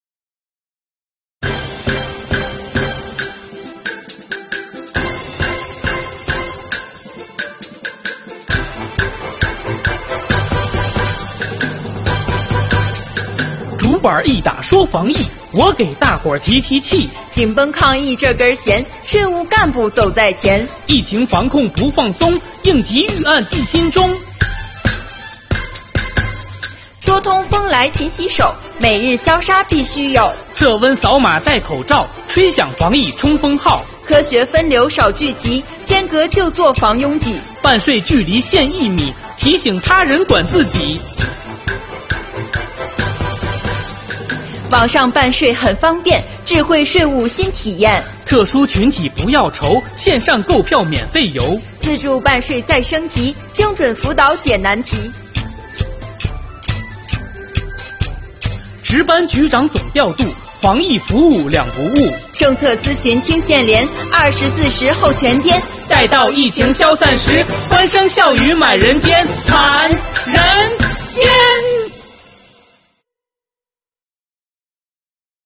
动漫 | 办税服务厅防疫快板书